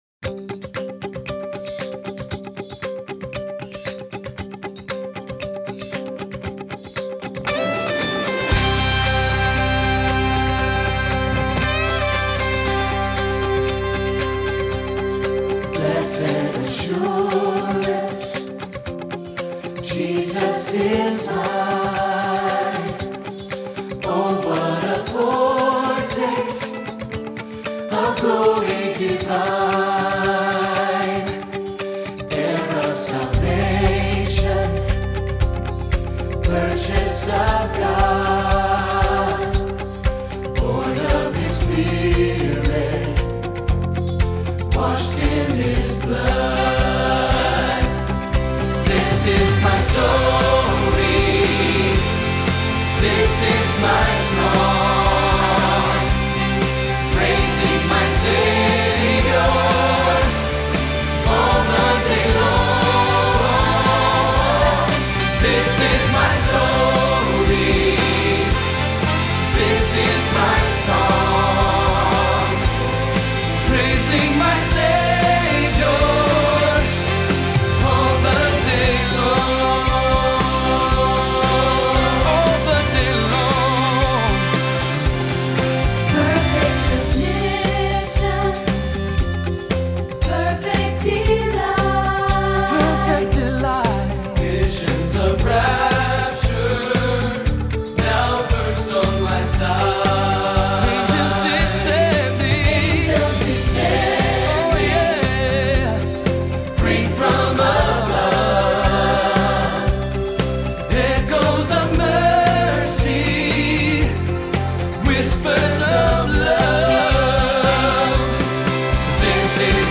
Here are recordings of the music for Ash Wednesday: